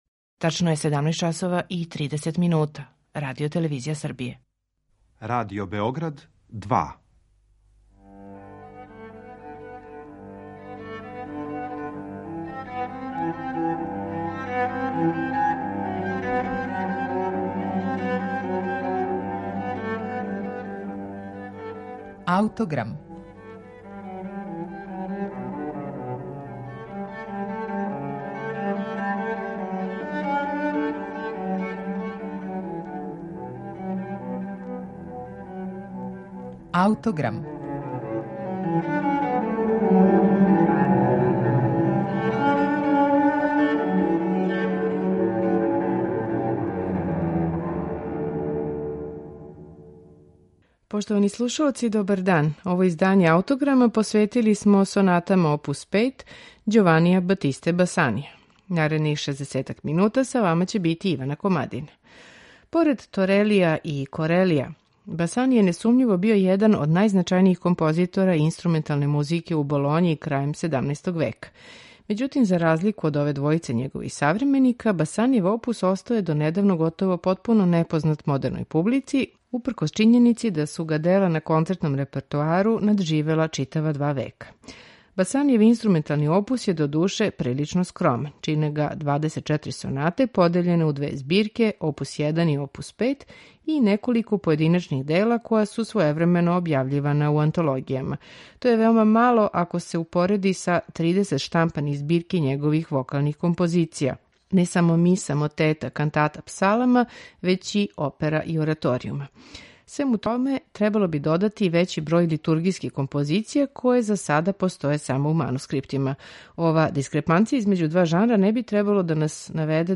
У данашњем Аутограму слушаћете првих седам соната за два или три инструмента и basso continuo из збирке опус 5 Ђованија Батисте Басанија, у интерпретацији чланова ансамбла Stil Moderno.